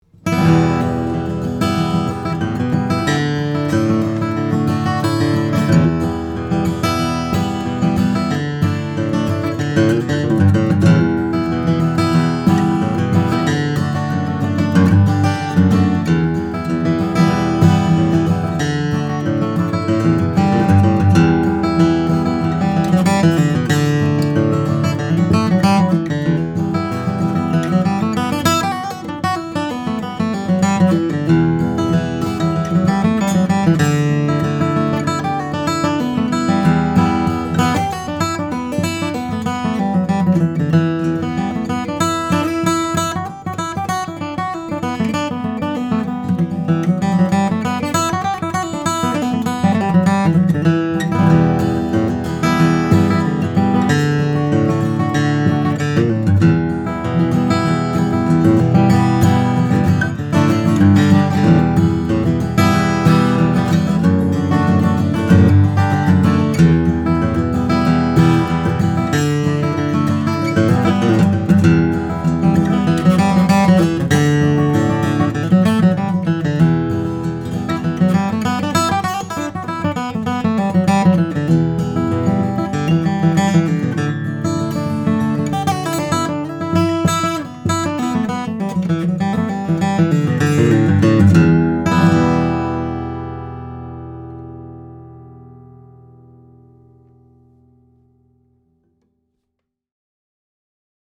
Brazilian Rosewood back and sides, Sitka Spruce on top, and a 1973-minted dime inlaid into the third fret to memorialize Jim’s passing.